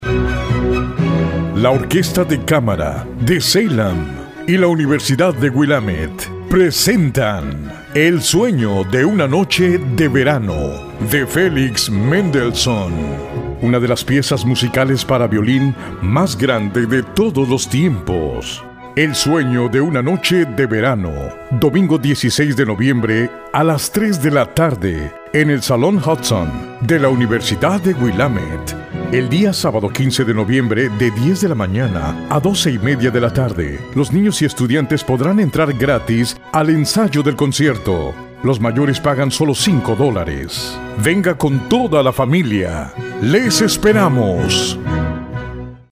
Gracias a la Orquesta de Cámara de Salem por publicar el cartel del concierto completamente en español y a Que Onda Radio por producir esta cuña de radio.